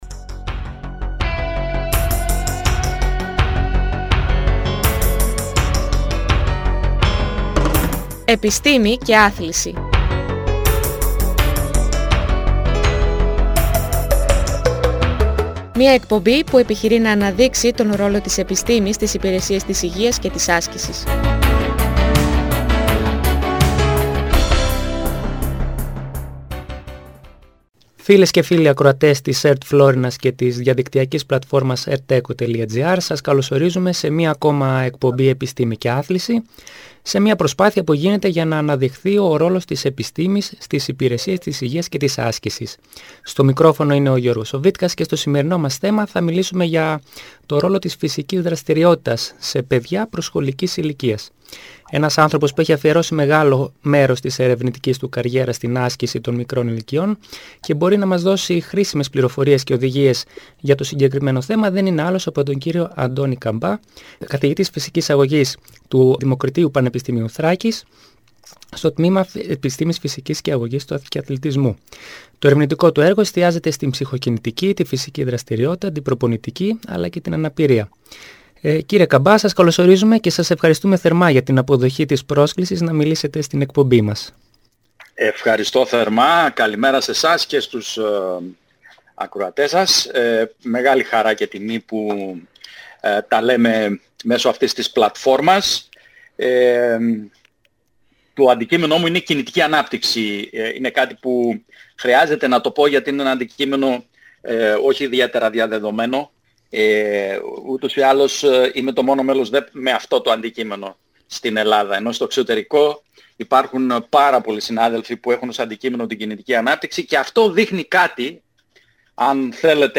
Μια εκπαιδευτική εκπομπή όπου διακεκριμένοι καθηγητές και επιστήμονες, αναδεικνύουν τον ρόλο της επιστήμης στις υπηρεσίες της υγείας και της άσκησης.